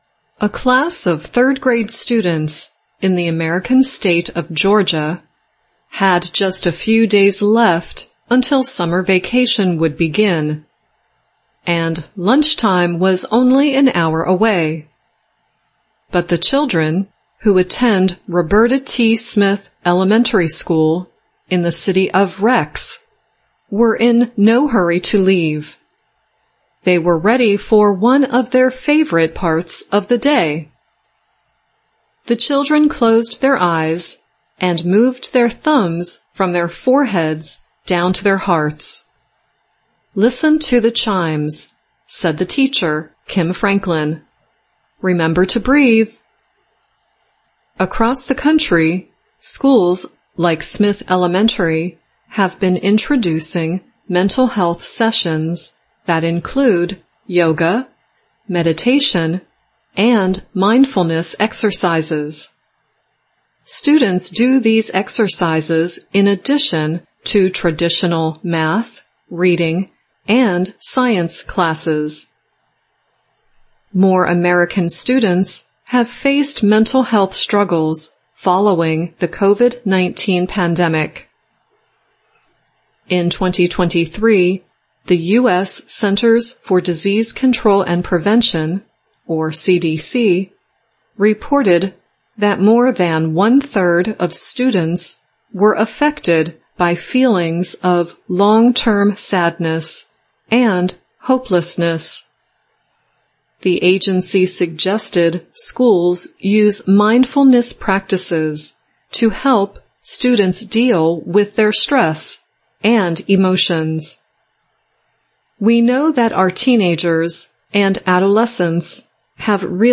2024-08-14 [Education Report] More US Schools Are Taking Breaks for Meditation
VOA慢速英语逐行复读精听提高英语听力水平